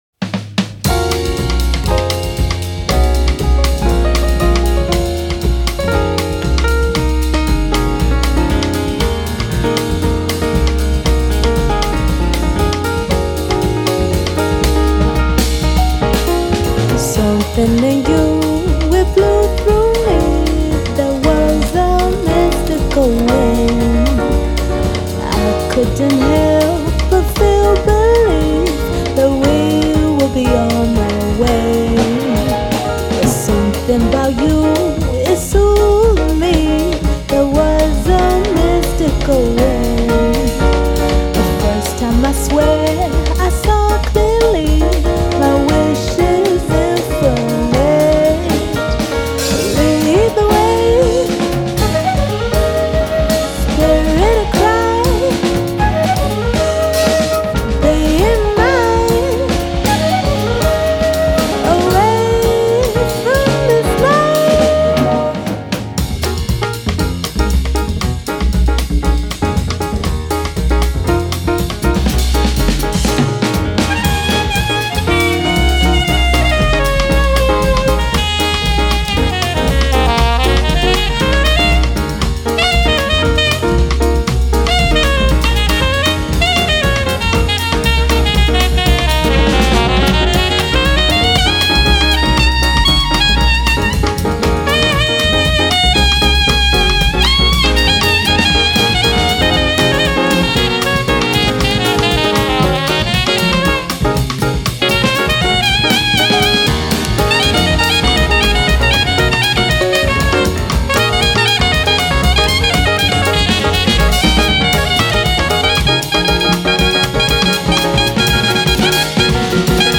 ジャンル(スタイル) JAZZ / JAPANESE